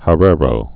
(hə-rârō, hĕrə-rō)